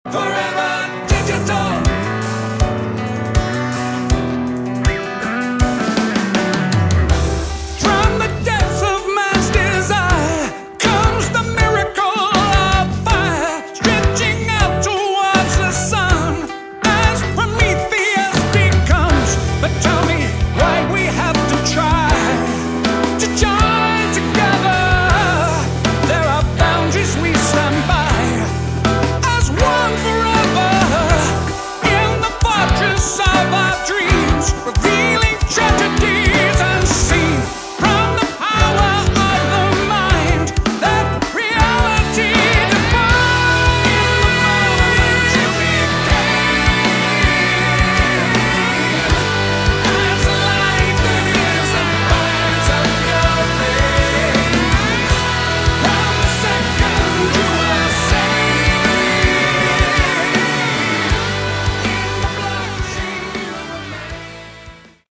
Category: Prog Rock
vocals
guitars, bass
drums
keyboards, Hammond organ, piano
Nice retro prog rock.